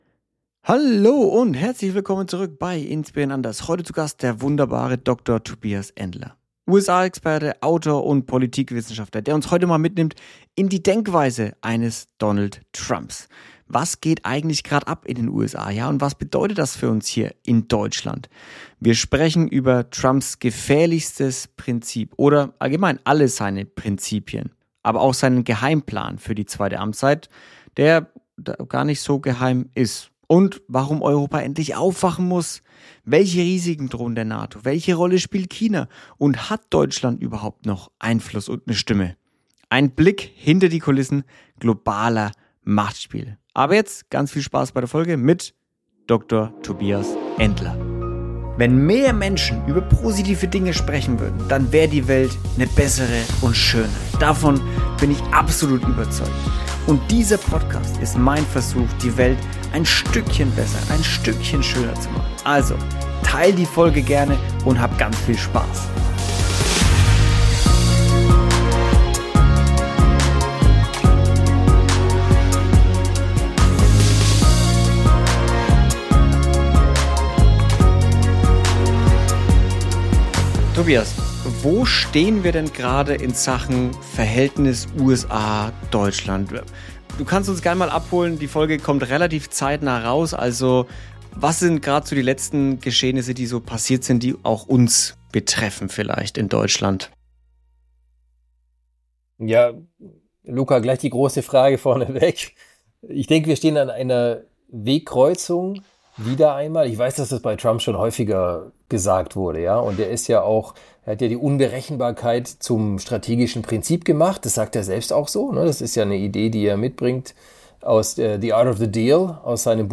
ein leidenschaftlicher Sportjournalist, der sich intensiv mit der Entwicklung des modernen Fußballs auseinandersetzt.